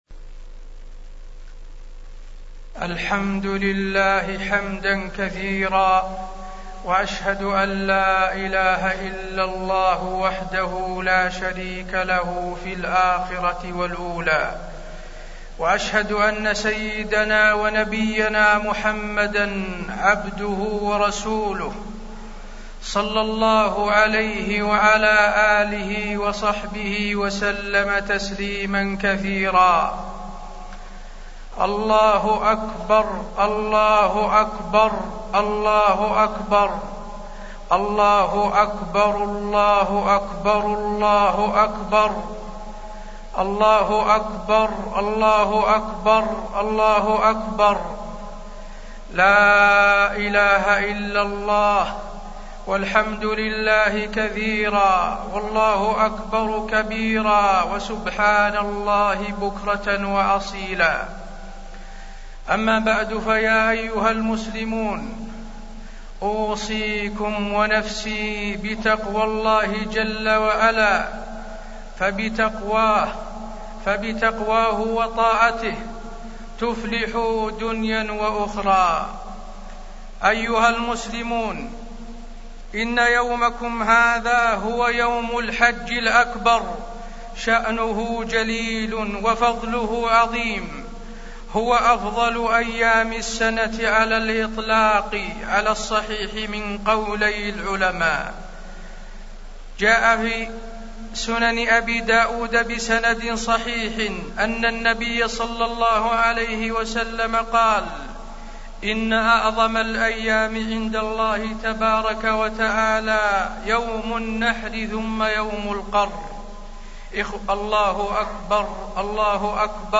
خطبة عيد الأضحى - المدينة - الشيخ حسين آل الشيخ
المكان: المسجد النبوي